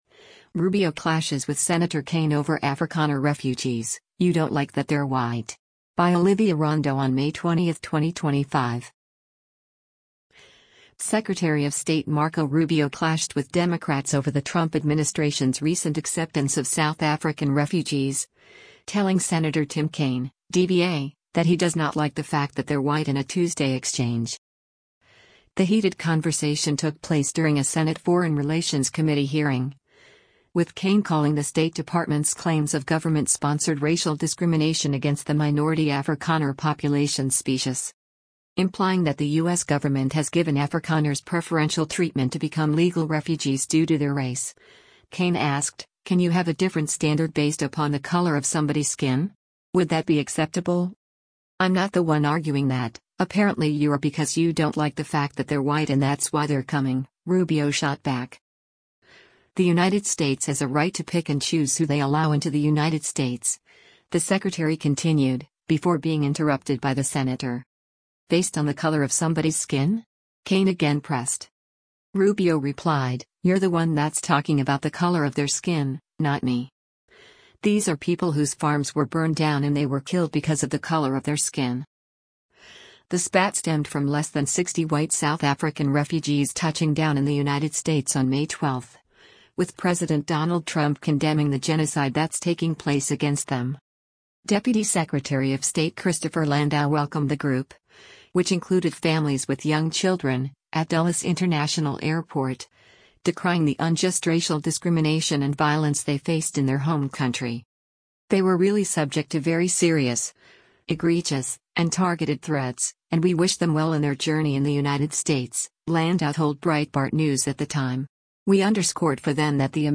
The heated conversation took place during a Senate Foreign Relations Committee hearing, with Kaine calling the State Department’s claims of “government-sponsored racial discrimination” against the minority Afrikaner population “specious.”